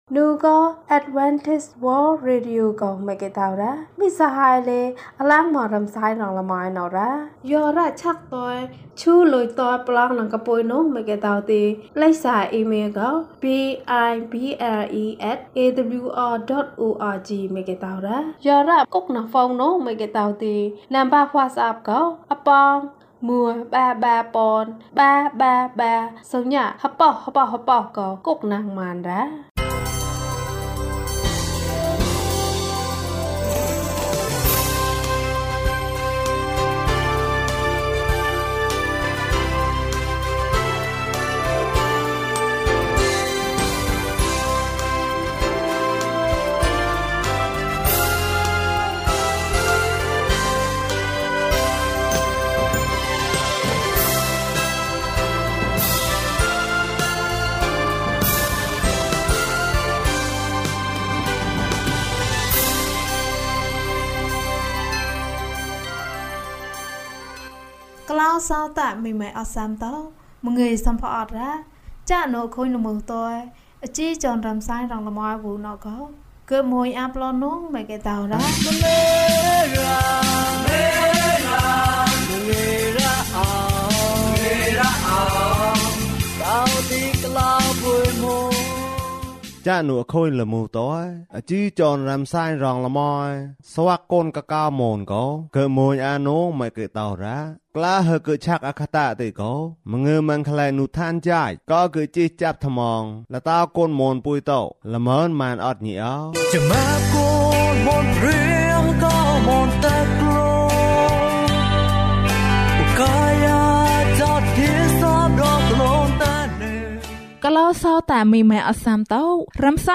ဧဝံဂေလိတရား။၀၂ ကျန်းမာခြင်းအကြောင်းအရာ။ ဓမ္မသီချင်း။ တရားဒေသနာ။